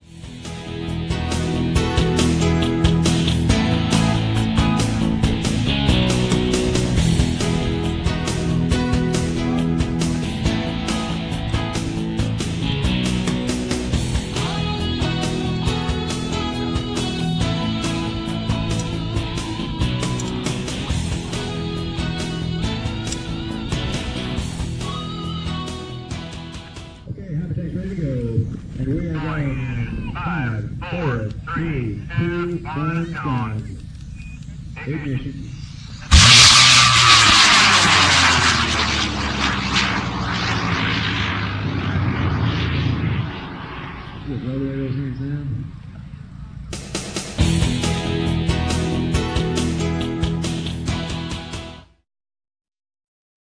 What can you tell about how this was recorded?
See some of the cool video taken at LDRS: